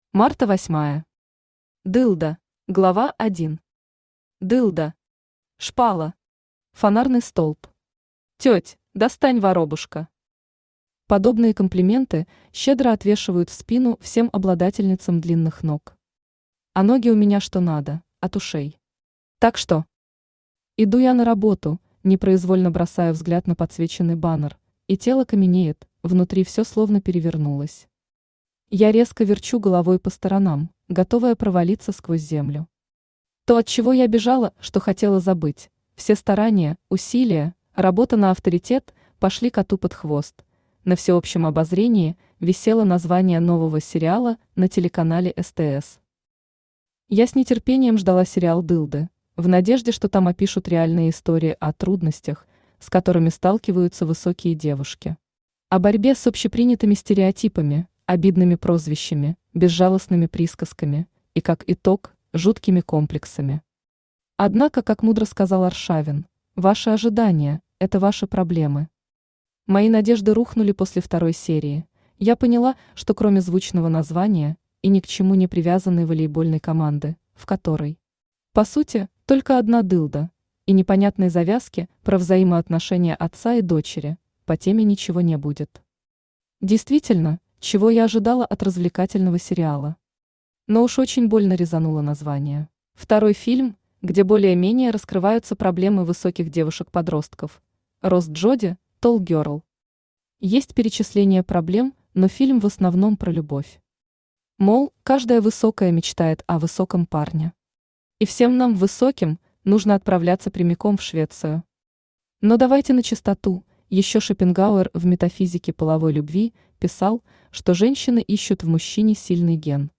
Aудиокнига Дылда Автор Марта Восьмая Читает аудиокнигу Авточтец ЛитРес.